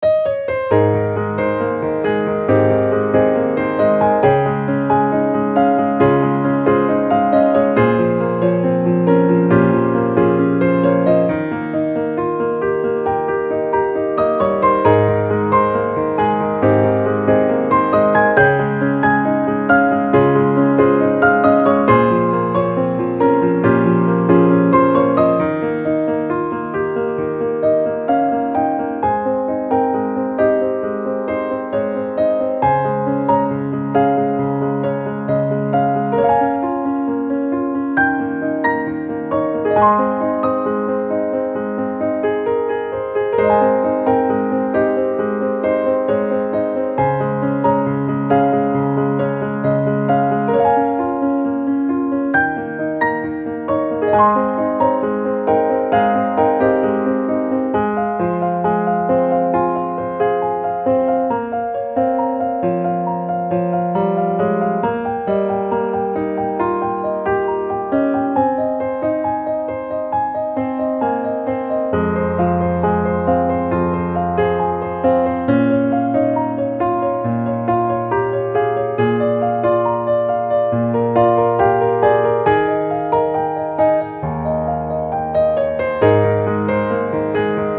ogg(L) 楽譜 癒し まったり 暖かみ
包み込む癒しの旋律。